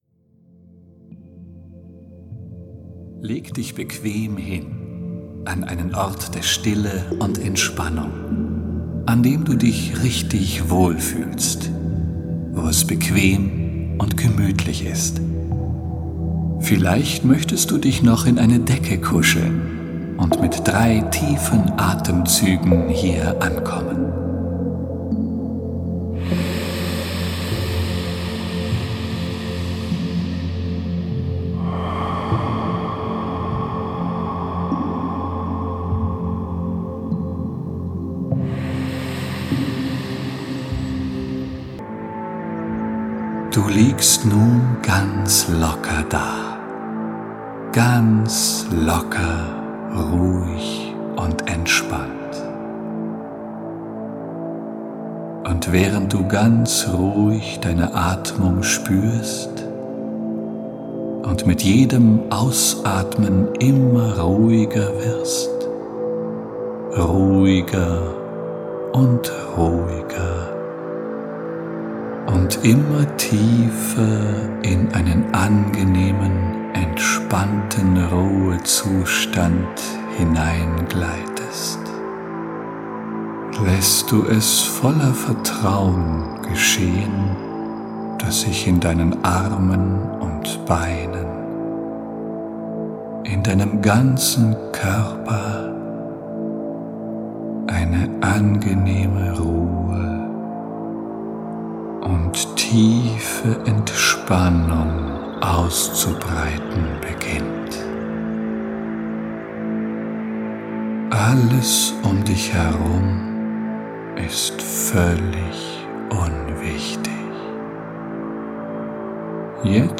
Genre: Meditation